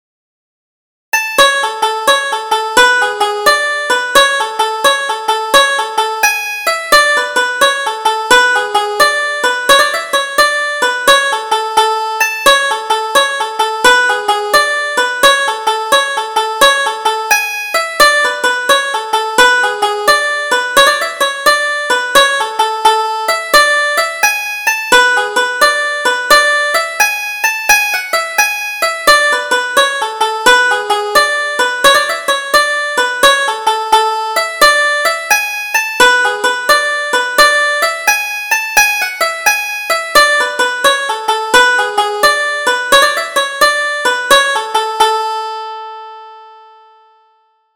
Double Jig: The Christening